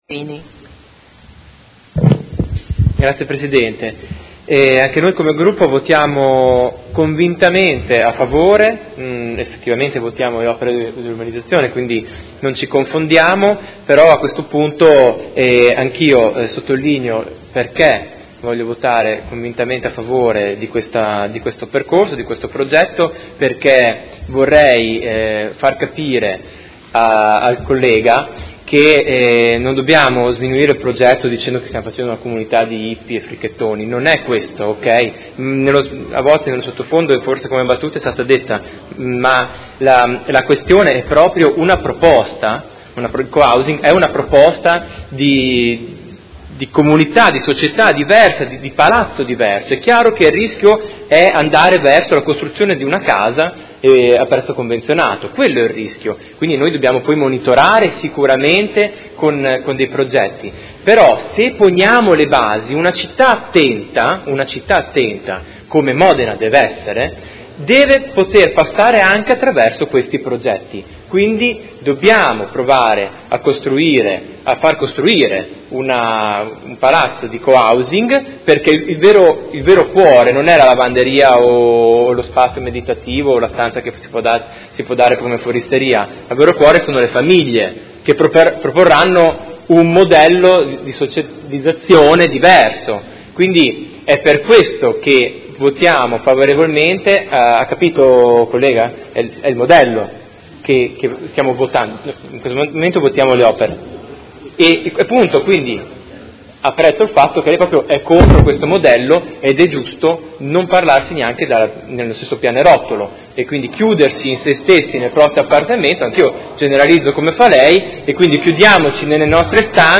Seduta del 3/12/2015.
Dichiarazione di voto